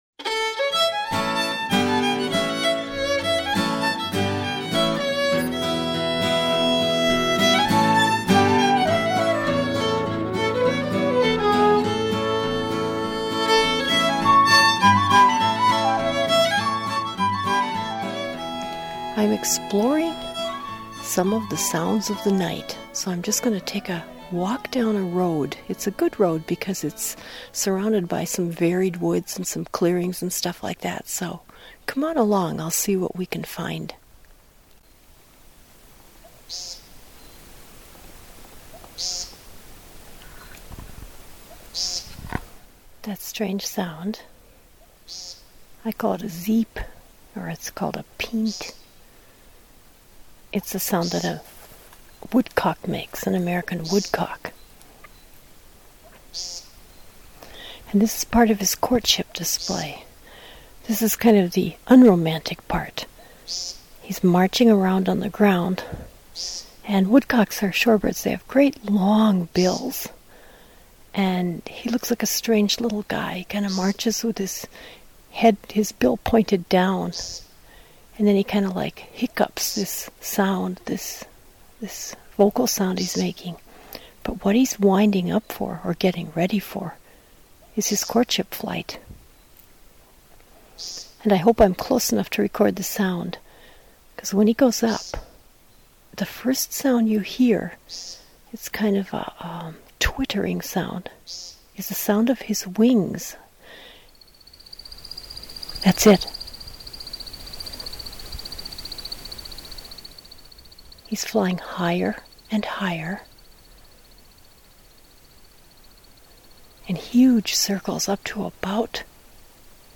Field Notes: American woodcock